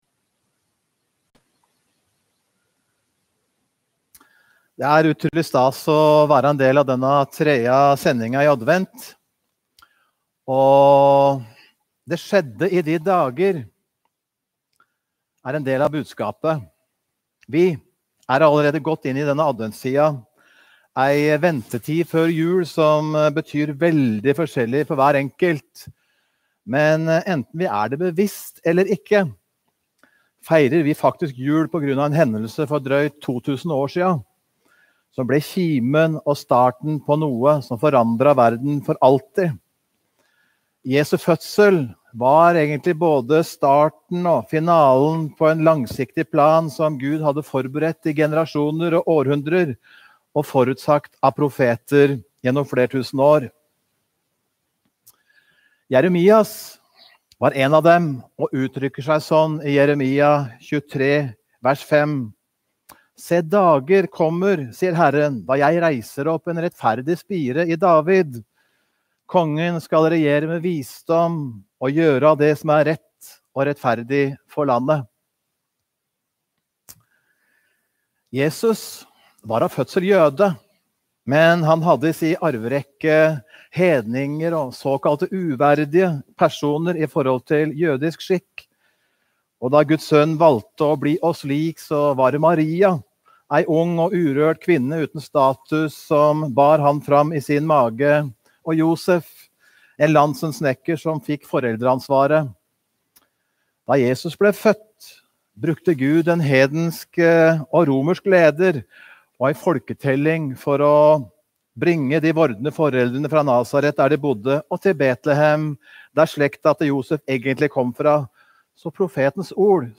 Adventgudstjeneste